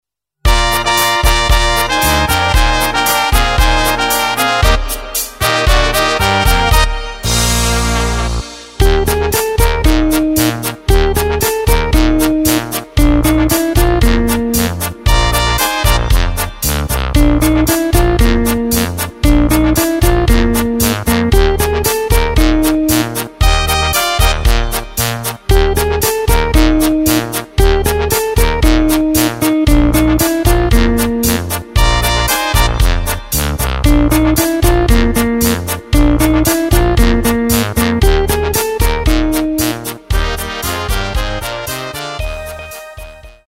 Takt:          4/4
Tempo:         115.00
Tonart:            Bb
Schlager in Blasmusik-Besetzung aus dem Jahr 2019!